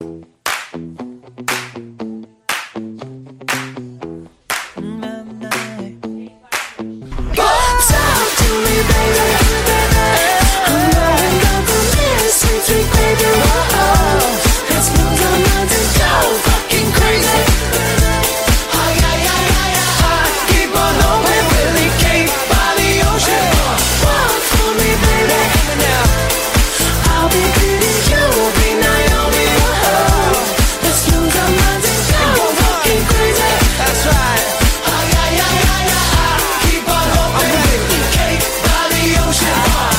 • Качество: 128, Stereo
зажигательные